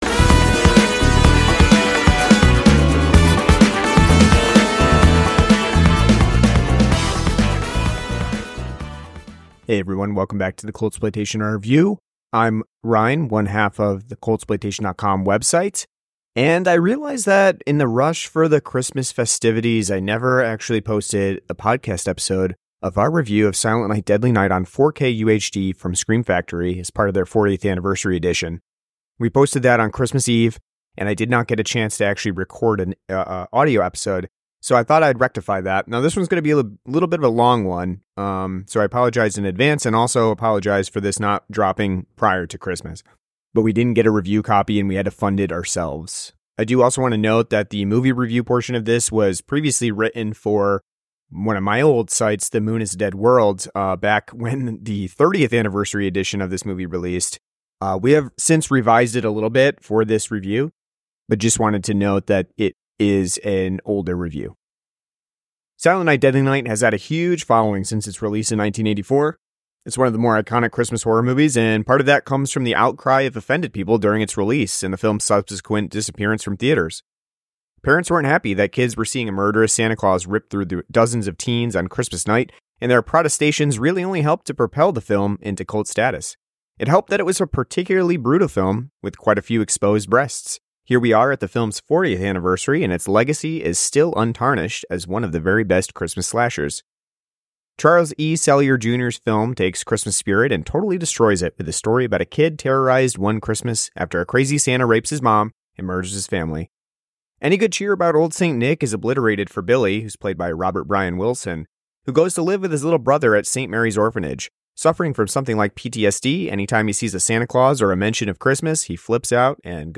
An audio review of Silent Night, Deadly Night on 4K UHD from Scream Factory.